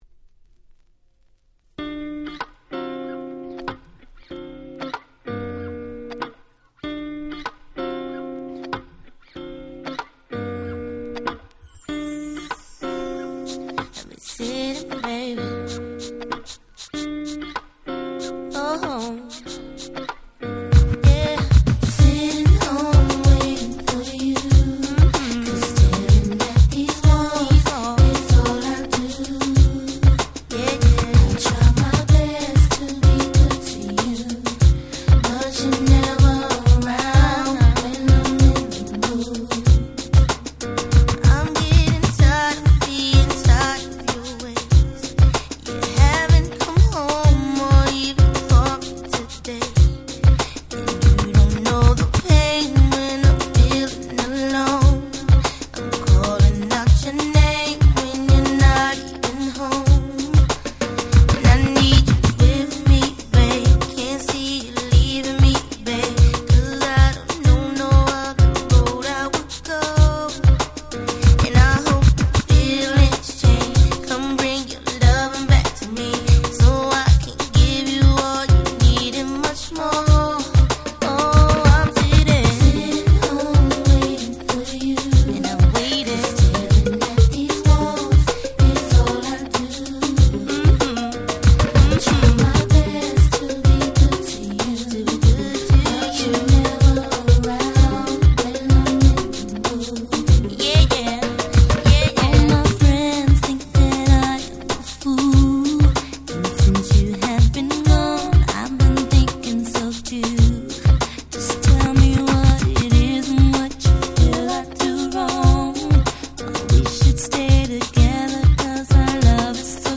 99' Smash Hit R&B !!